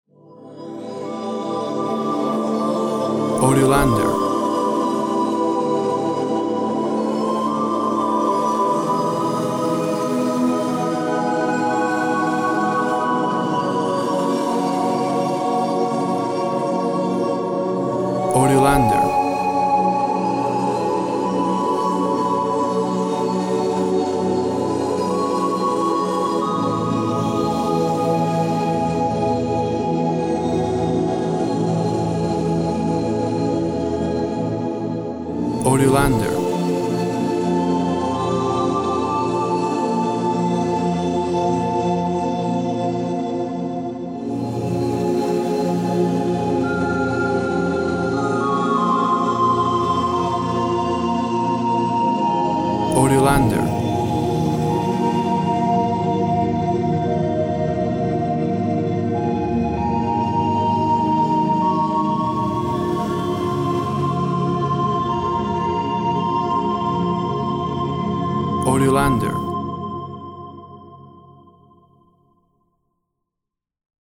Dreamy sounds of synth, choir, and wooden flute.
WAV Sample Rate 24-Bit Stereo, 44.1 kHz
Tempo (BPM) 58